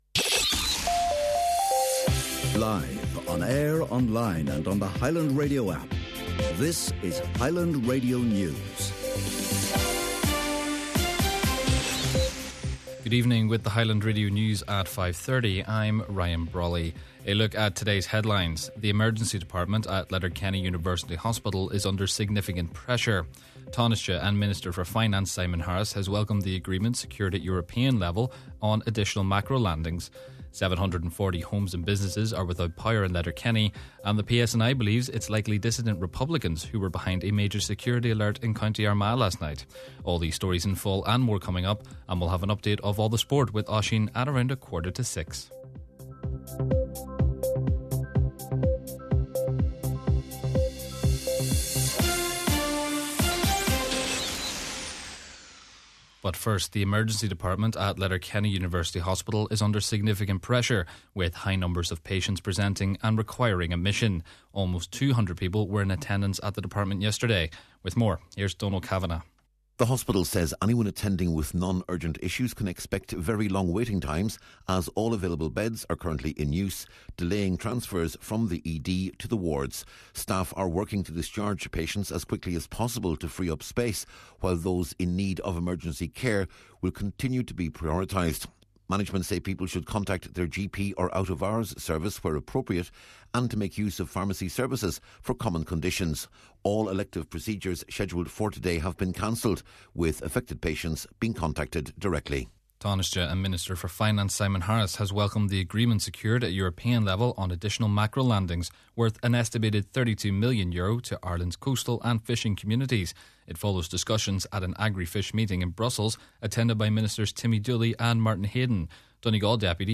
Main Evening News, Sport, and Obituary Notices – Tuesday, March 31st